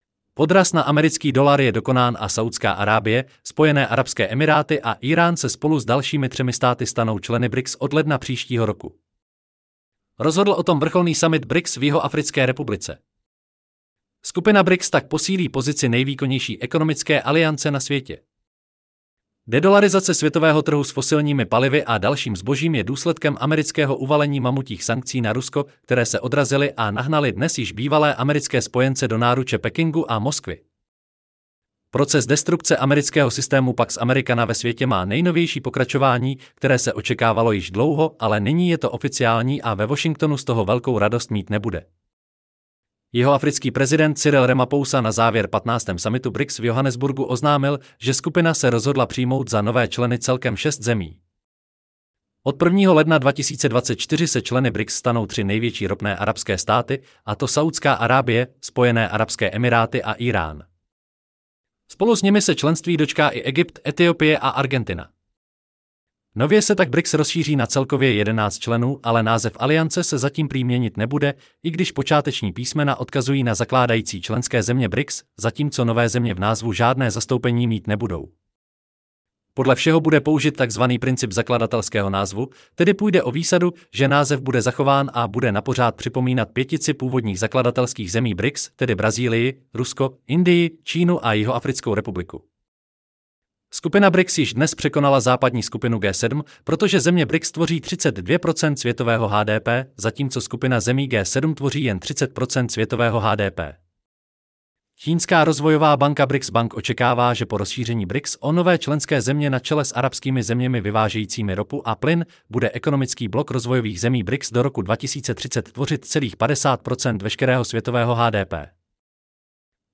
Celý článek si můžete poslechnout v audio videu zde: